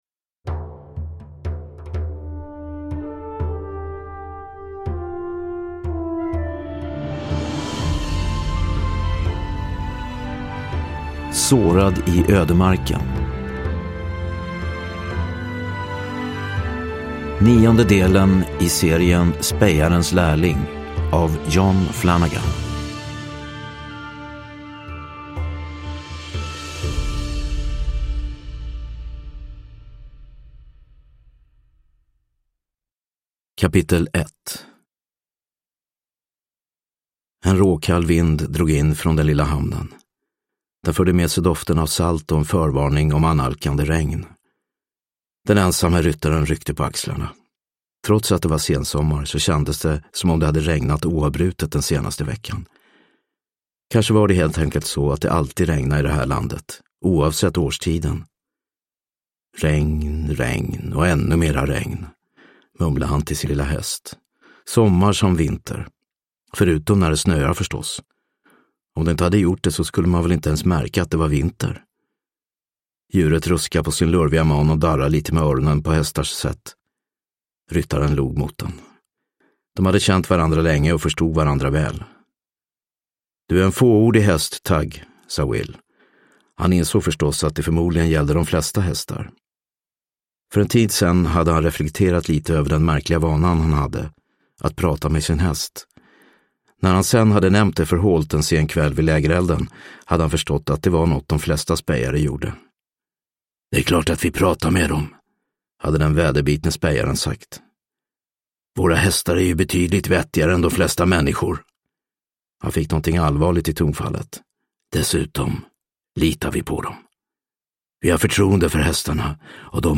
Sårad i ödemarken – Ljudbok – Laddas ner